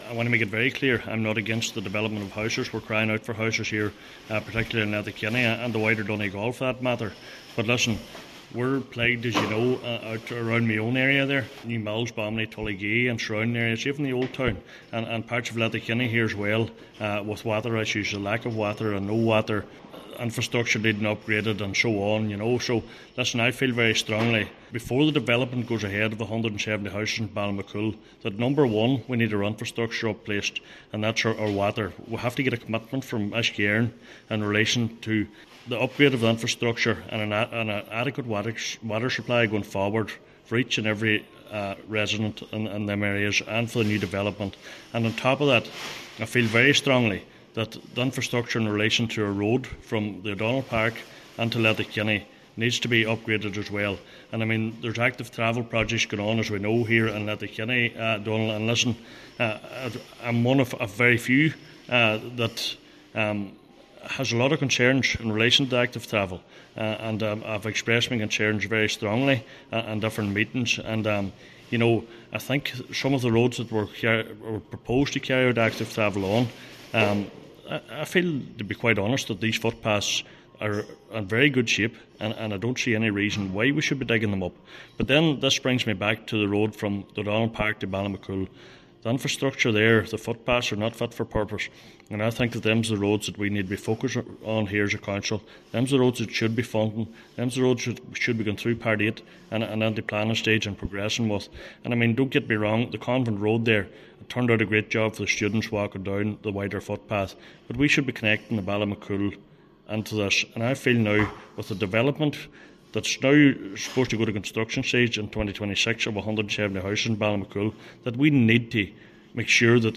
Cllr Kelly says he believes Uisce Eireann in particular is holding up progress………